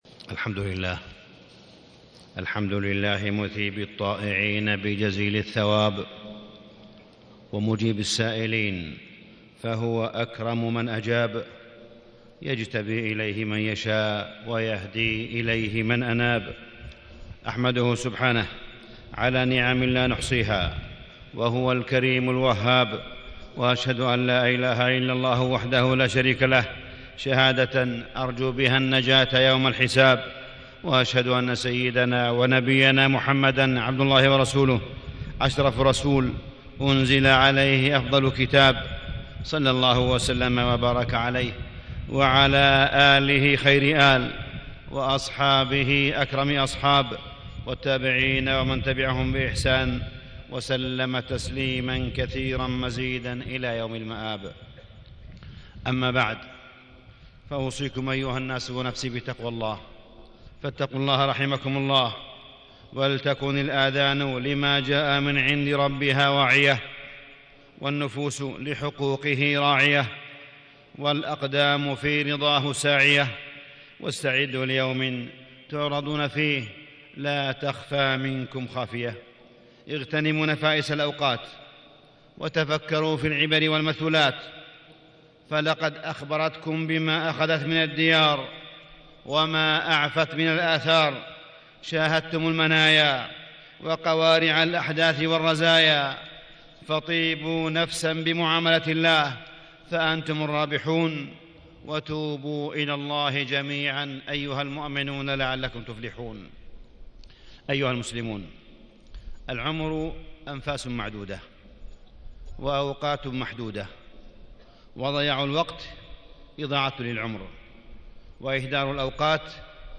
تاريخ النشر ١ جمادى الأولى ١٤٣٦ هـ المكان: المسجد الحرام الشيخ: معالي الشيخ أ.د. صالح بن عبدالله بن حميد معالي الشيخ أ.د. صالح بن عبدالله بن حميد الإعلام الجديد بين الواقع والمأمول